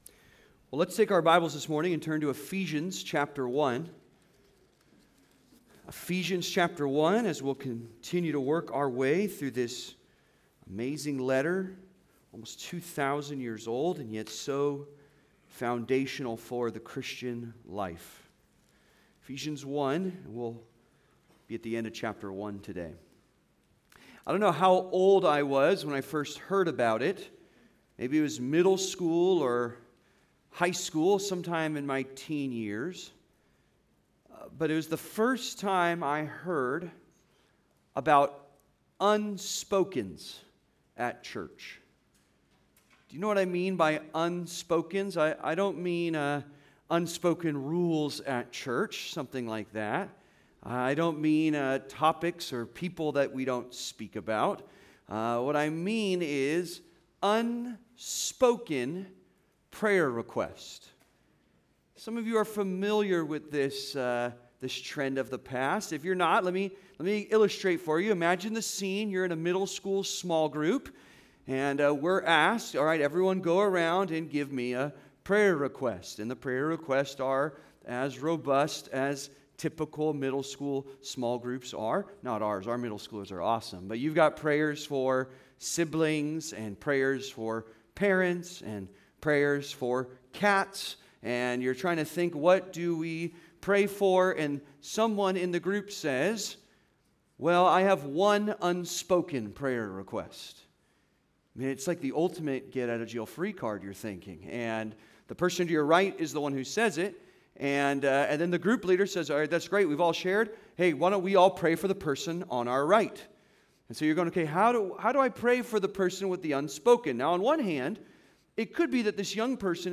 Praying for Sight (Sermon) - Compass Bible Church Long Beach